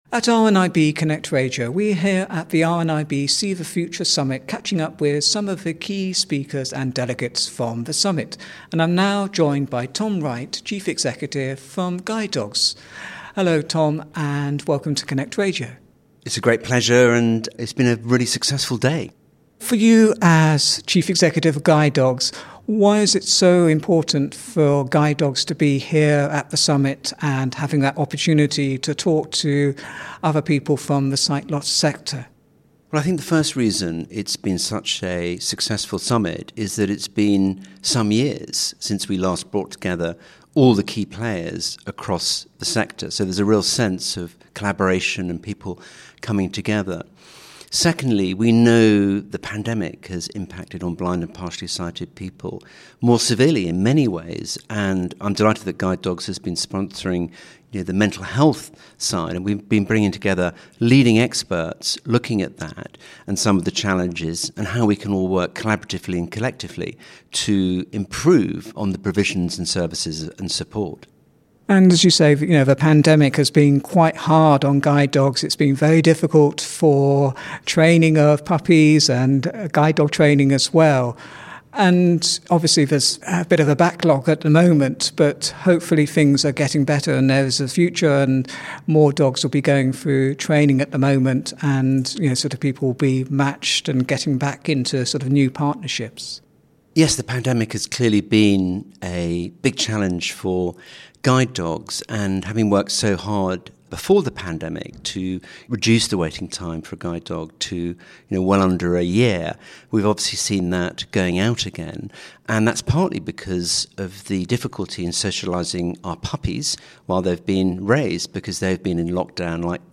RNIB See the Future Differently Summit - Interview